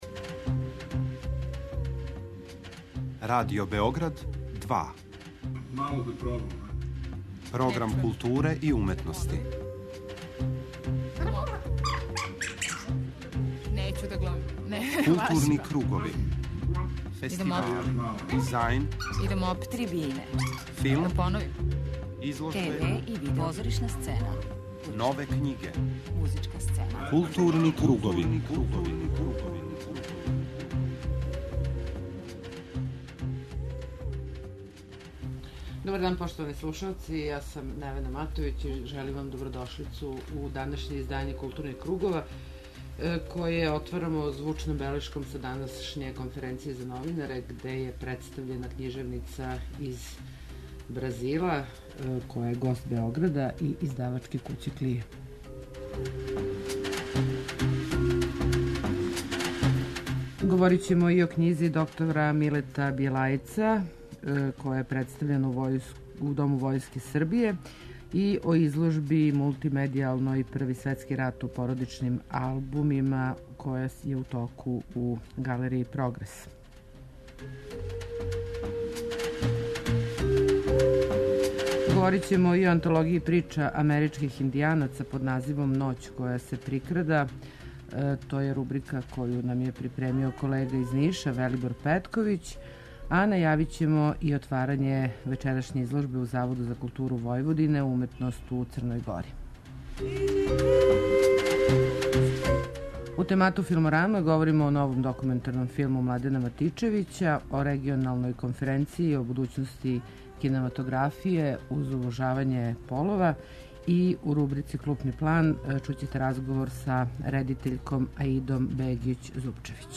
Поред професора, филмских редитеља, продуцената и драматурга из Србије и са еx југословенских простора, на њој је учествовало и око 70 студената филмских академија из Скопја, Сарајева, Бања Луке, Новог Сада, Косовске Митровице и Београда, који ће са нама поделити своје утиске и запажања.
преузми : 53.48 MB Културни кругови Autor: Група аутора Централна културно-уметничка емисија Радио Београда 2.